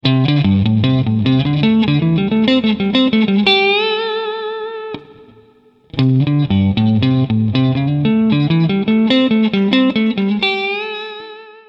Fraseggio blues 05
Il lick è suonato alternando due tipi di ritmica. Nella prima parte le terzine vengono suonate nel box 1 di A minore, spostandosi poi nel box 2 e cambiando anche il ritmo in sedicesimi.